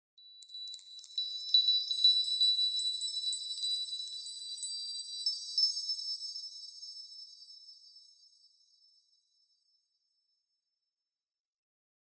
Звуки феи
Волшебная пыль мерцает и сверкает, посыпая звонкое дерево эффектом